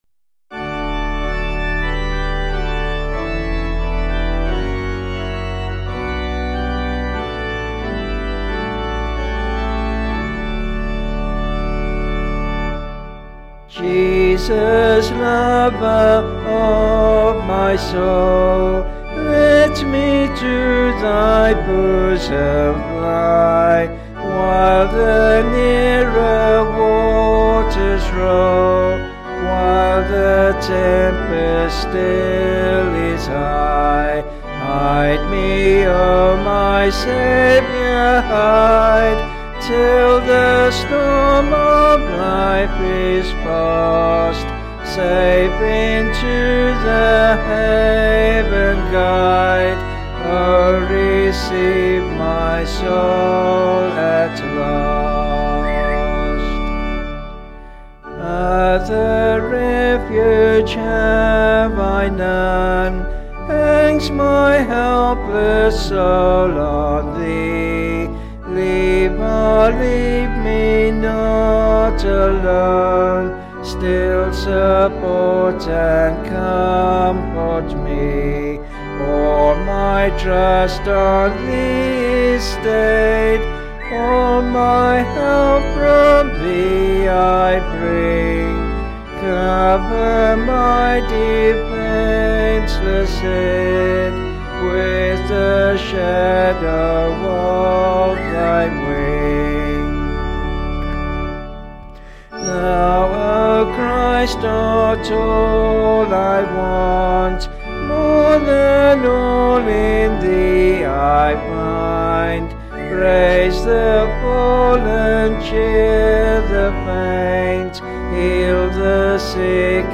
Vocals and Organ   264kb Sung Lyrics